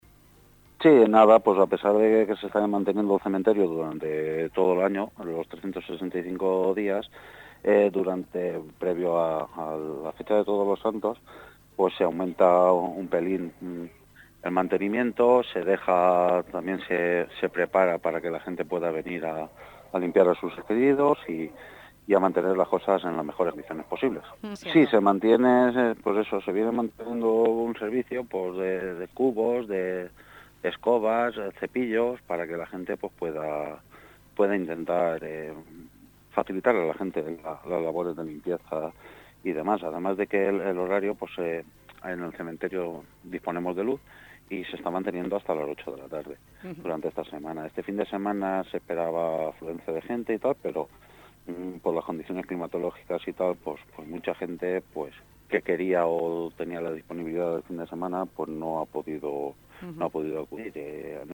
ENTREVISTA CON EL ALCALDE Y EL EDIL